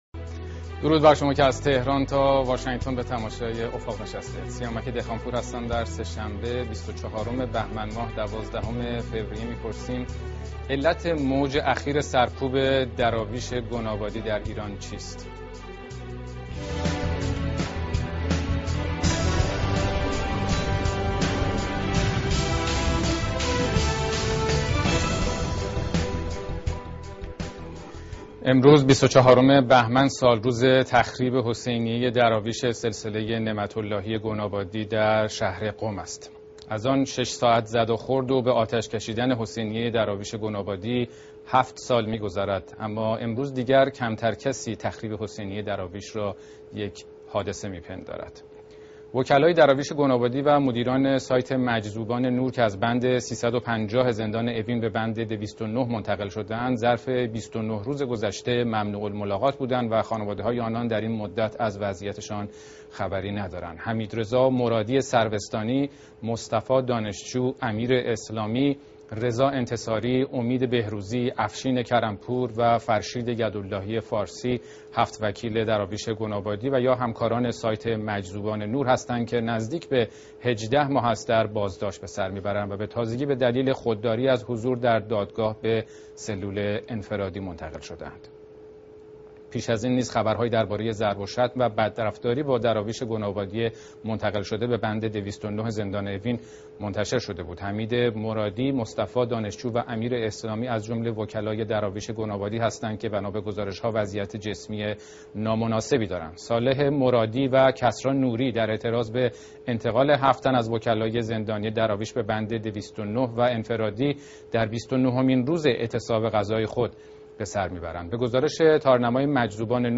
«سرکوب دراویش در ایران» گفتگوی افق با همسرهای دراویش در بند، وضعیت وکلای دراویش در اوین، طرح موسسه فرق و ادیان برای سرکوب دراویش و دگرباوران،